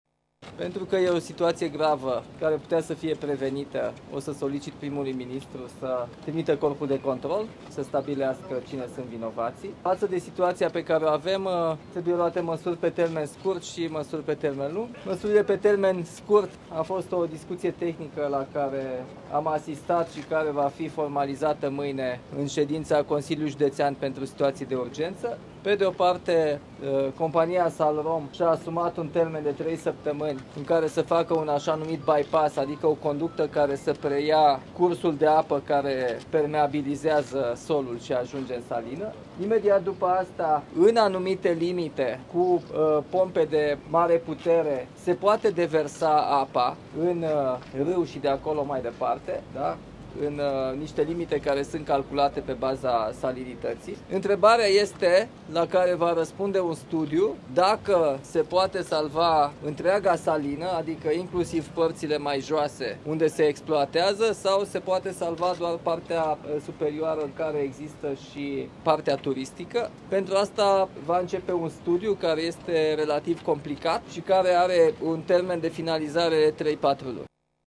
Nicușor Dan a participat la o şedinţă de lucru a tuturor instituţiilor implicate în intervenţia imediată: